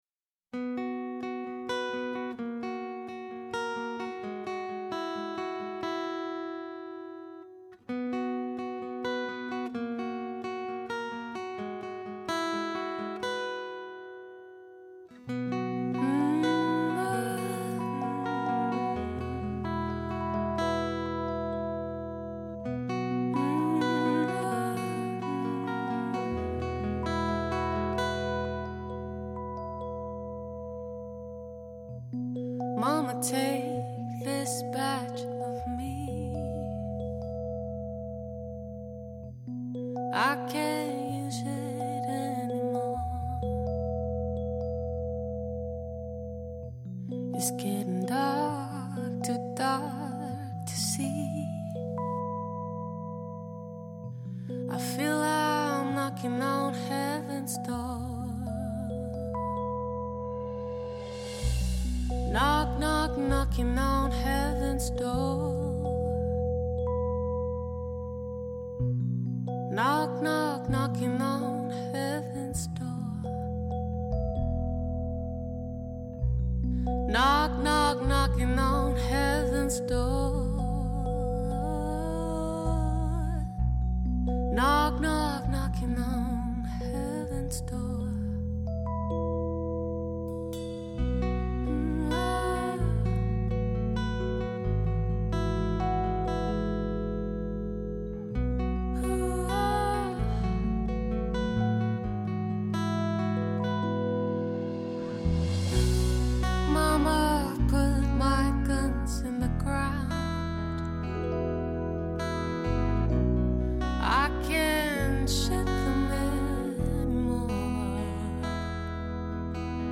R&B
歌聲甜美多變、展現高超唱功外
通透的女聲、紮實的鼓聲、定位精確的空間感，都讓發燒友欲罷不能，一聽再聽！